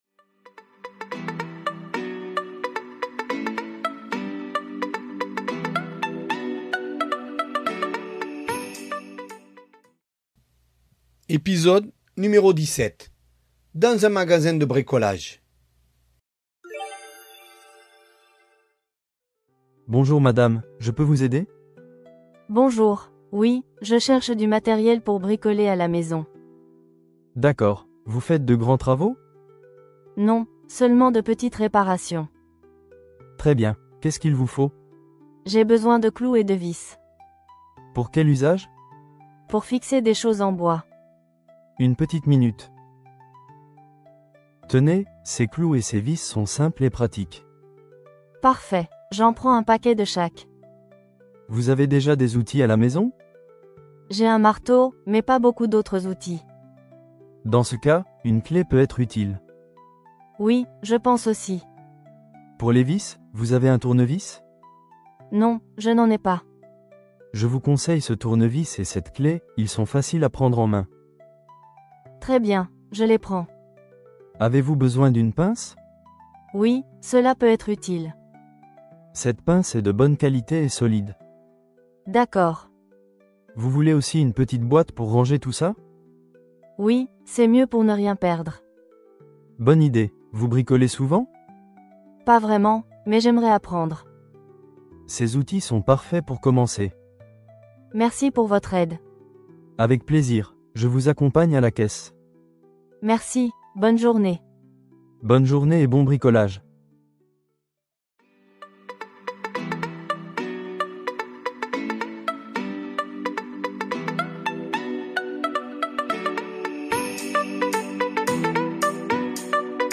Voici un dialogue pour les élèves de niveau A1 sur le bricolage qui permet de réviser les pronoms COD, EN et les adjectifs démonstratifs.